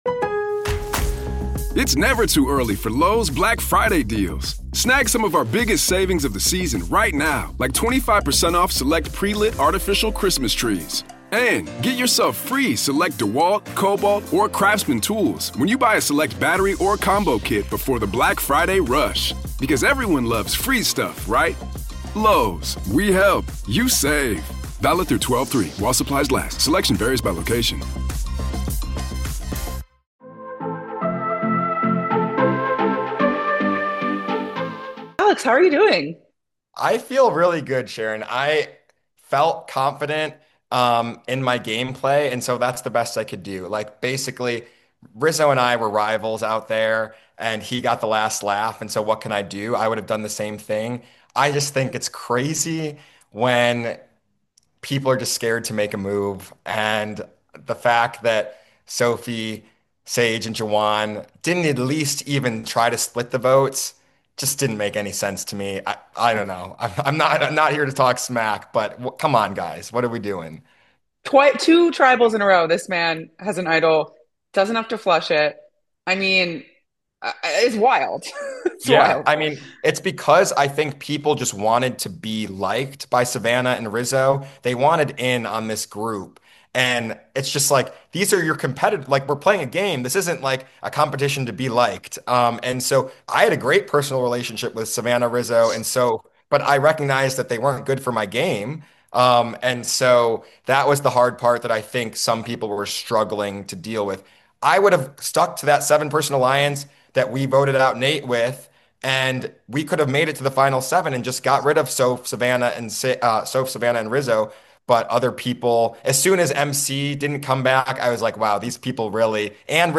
Survivor 49 Exit Interview: 9th Player Voted Out Speaks!